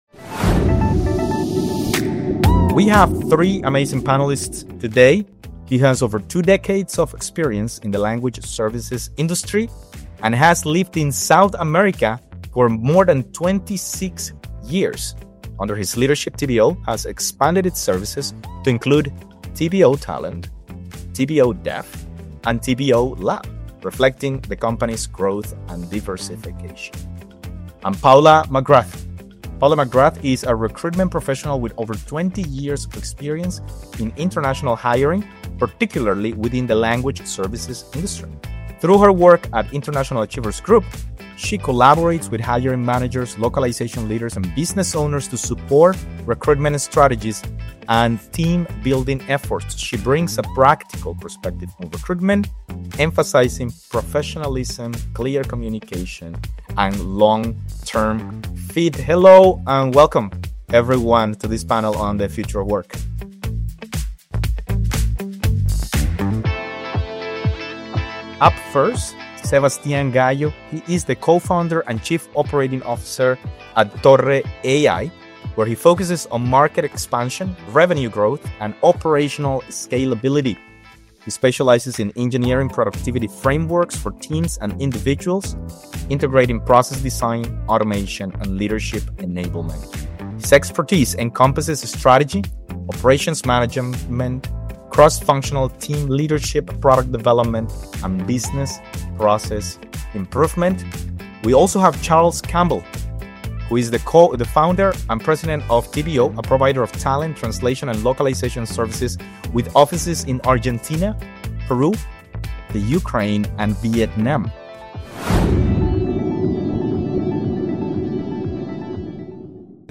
AI-Powered Language Tools for Translators and Interpreters in 2025 (Keynote) – LangTalent Podcast – Podcast